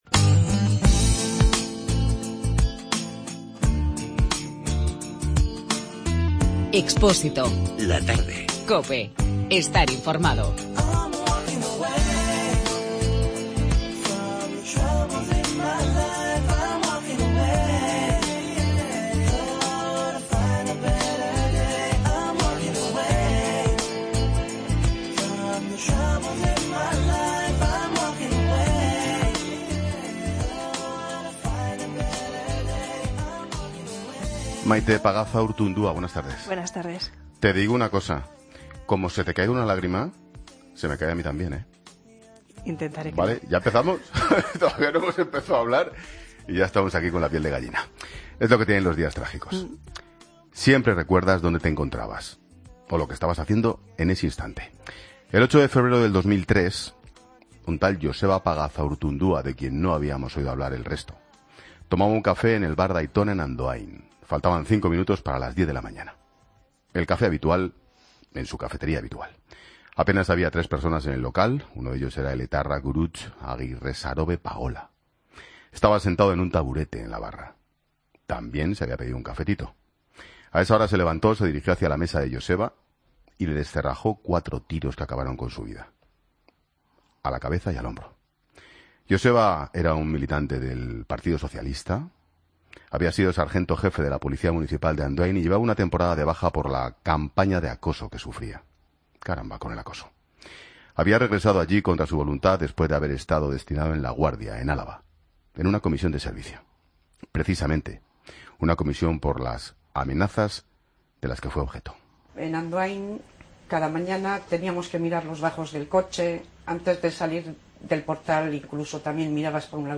Escucha la entrevista completa a Maite Pagazaurtundúa en La Tarde con Ángel Expósito